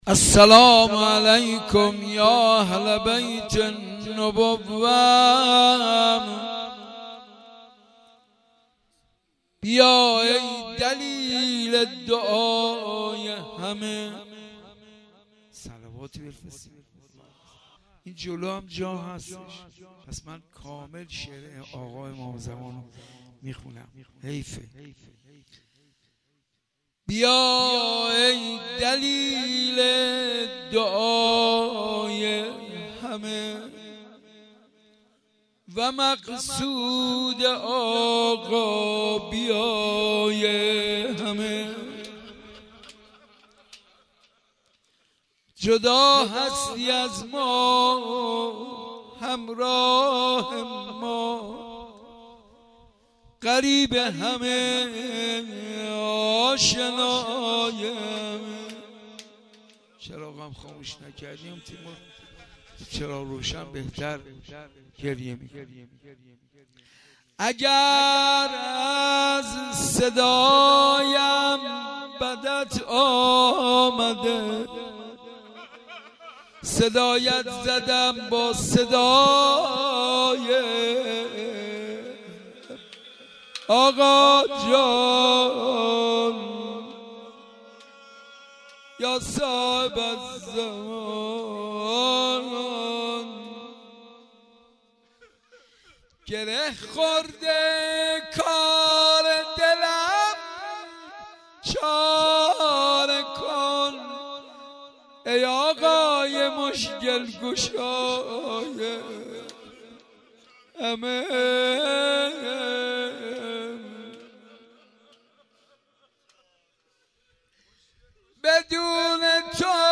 صوت مداحی
در بیت الحسن(ع)